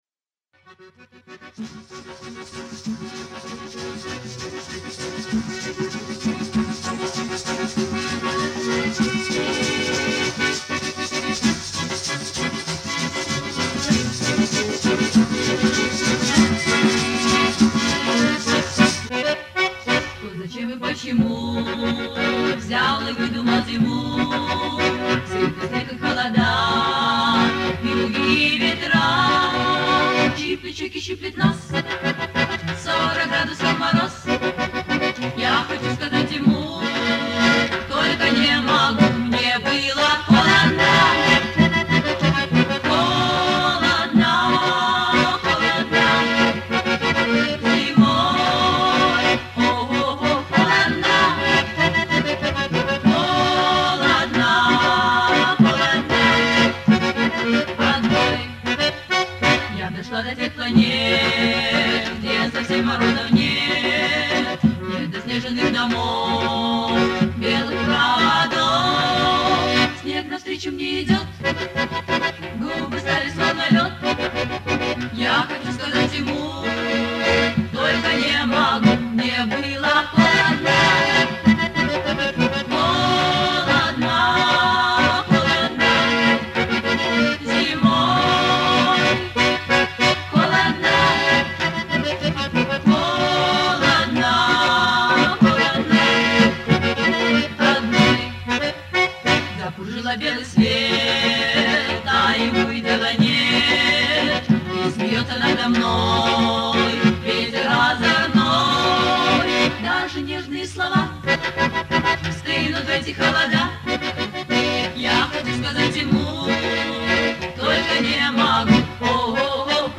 Это же молоденькие девчушки пели ,а не оперные певицы
Она сейчас в Нm.